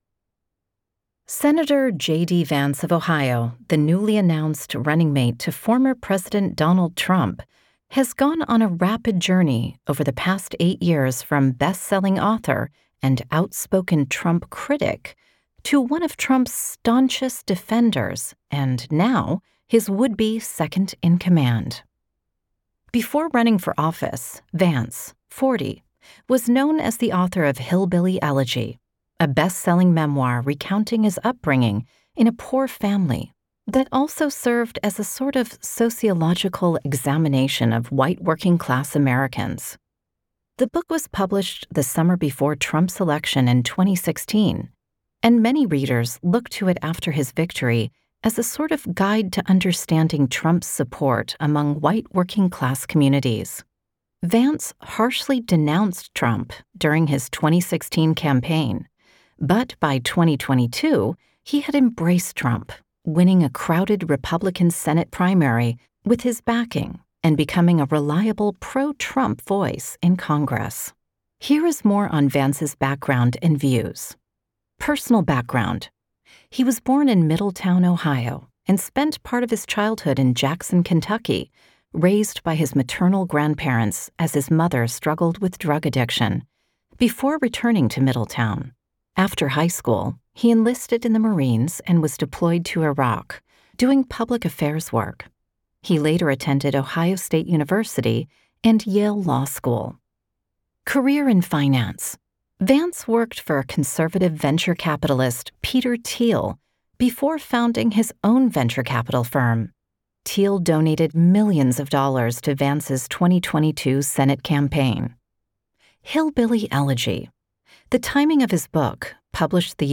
Speaker (American accent)